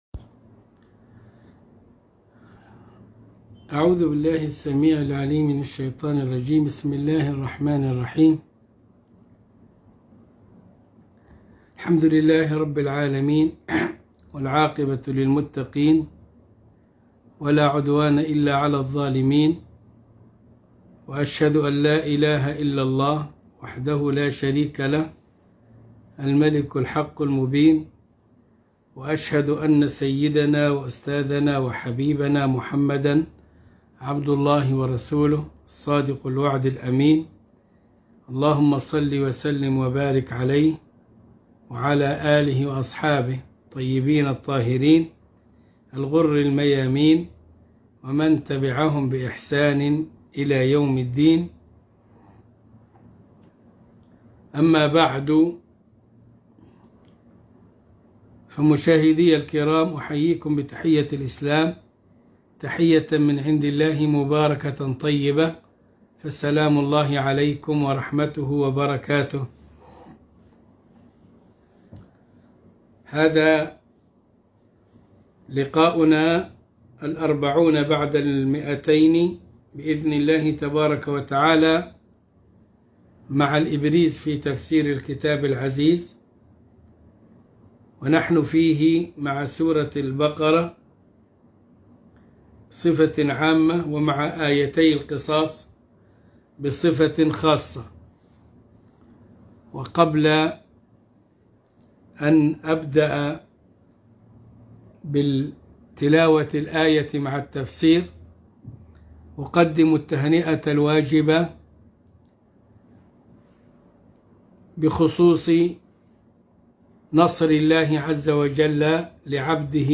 الدرس ٢٤٠ من الإبريز في تفسير الكتاب العزيز سورة البقرة الآية ١٧٨ وما بعدها